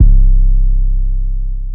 Metro 808 2 (C).wav